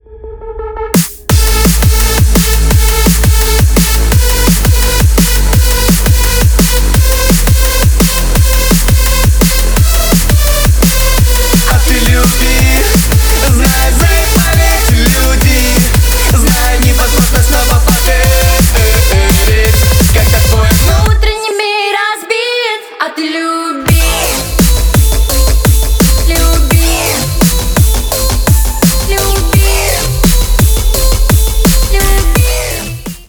бесплатный рингтон в виде самого яркого фрагмента из песни
Поп Музыка # Электроника